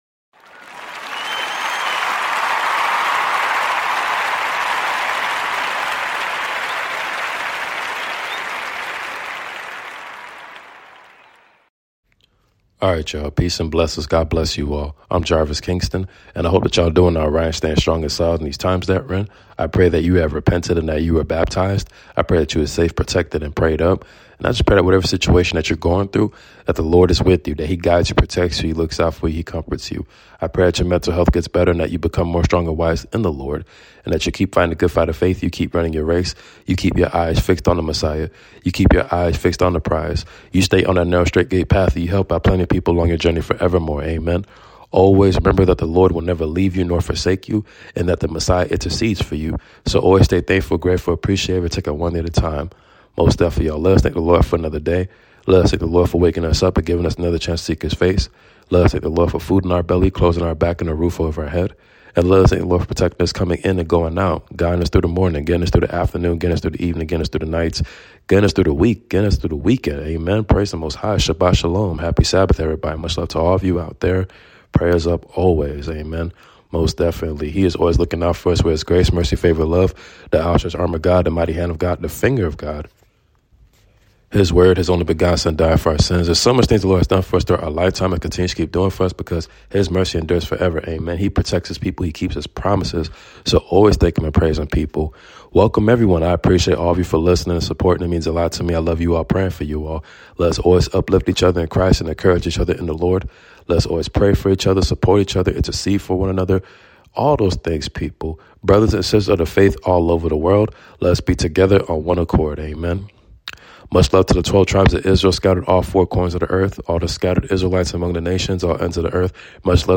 News roundup for this week prayers up